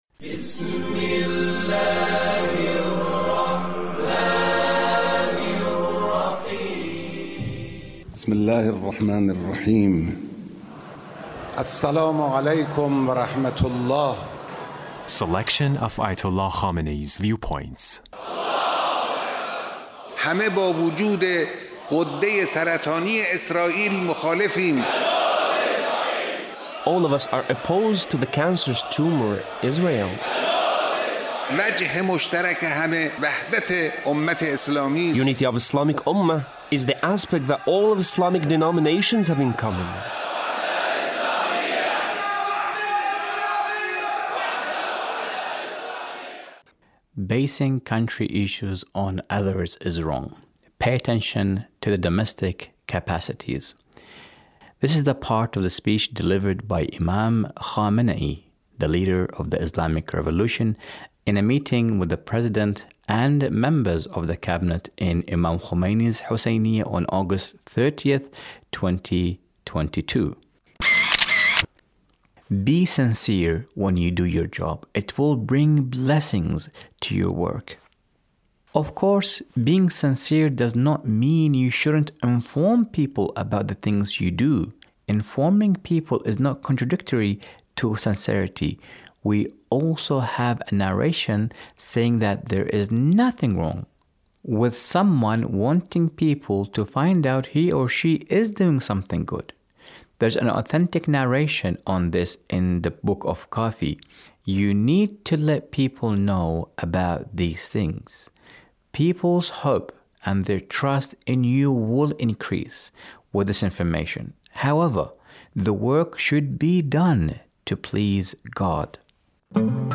Leader's Speech on a Gathering with Friday Prayer Leaders